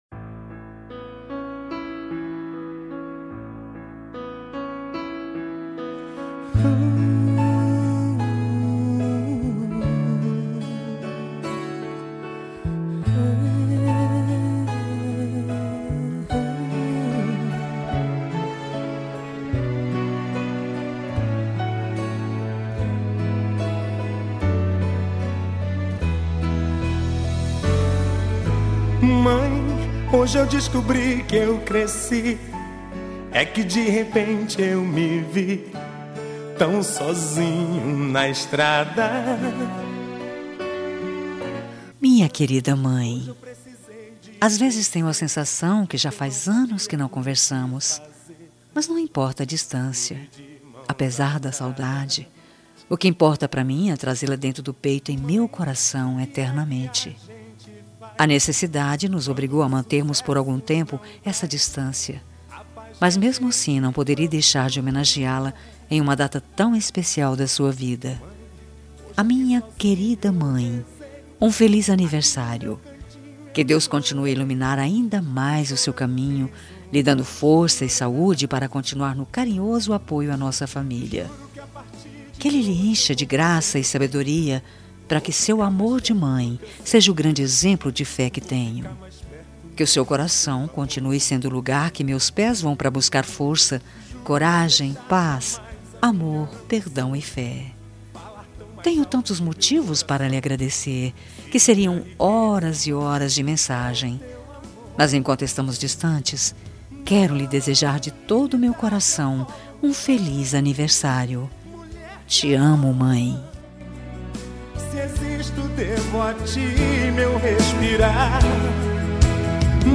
Telemensagem Aniversário de Mãe – Voz Feminina – Cód: 1418 – Distante – Linda